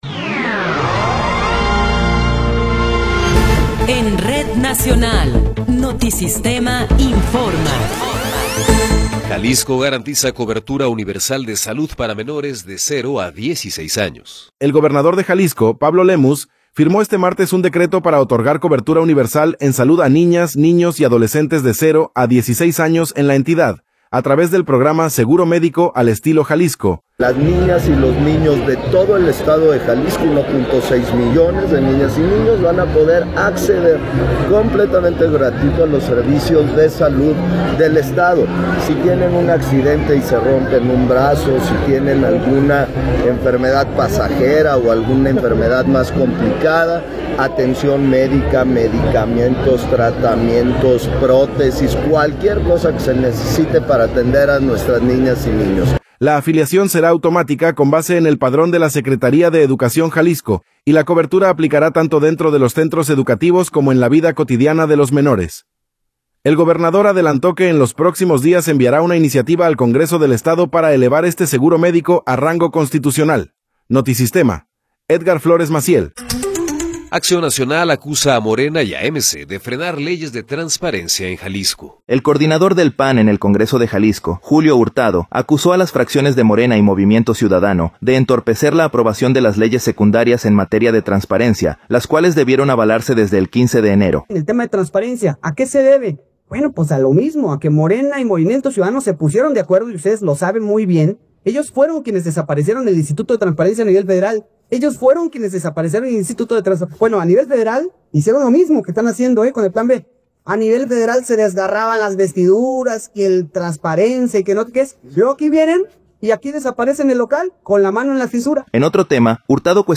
Noticiero 12 hrs. – 22 de Abril de 2026